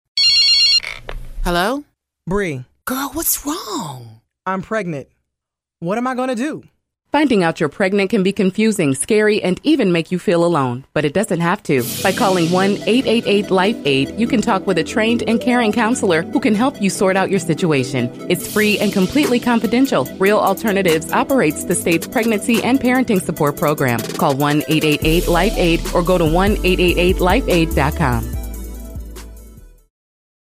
Radio Ad 3 - Real Alternatives
Click here to listen to the radio ad